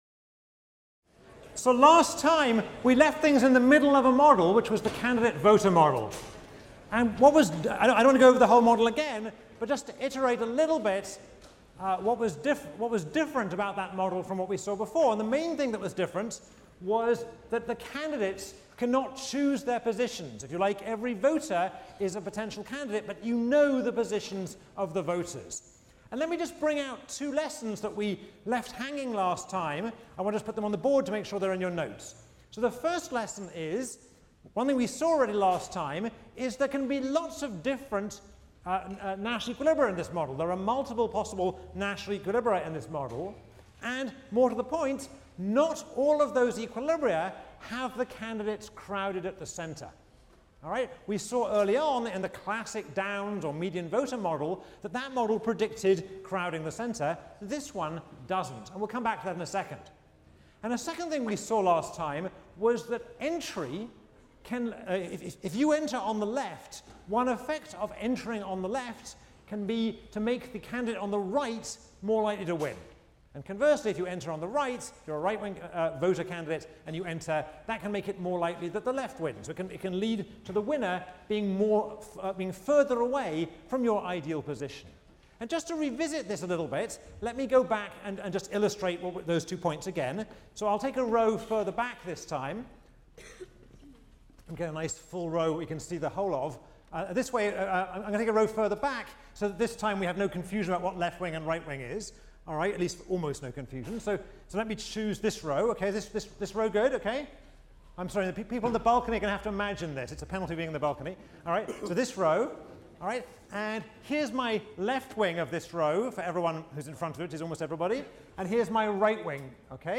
ECON 159 - Lecture 8 - Nash Equilibrium: Location, Segregation and Randomization | Open Yale Courses